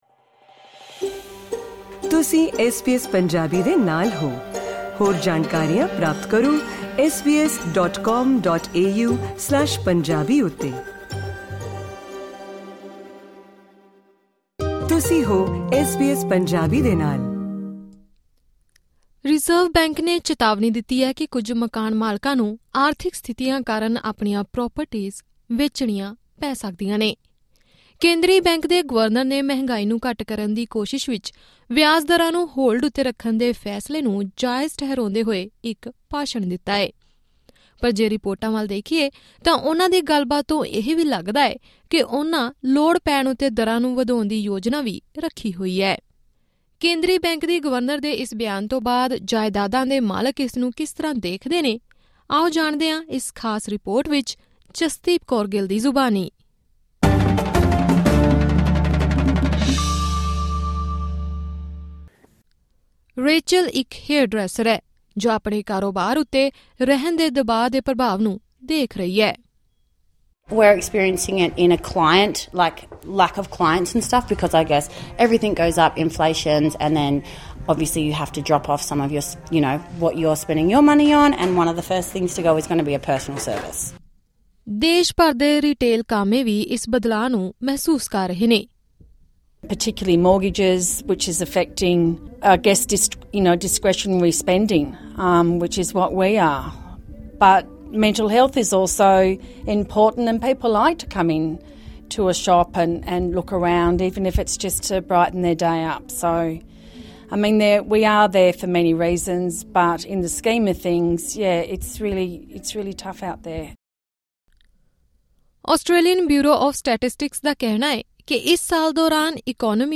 ਪੂਰੀ ਜਾਣਕਾਰੀ ਲਈ ਉੱਪਰ ਸਾਂਝੀ ਕੀਤੀ ਗਈ ਆਡੀਓ ਰਿਪੋਰਟ ਸੁਣੋ..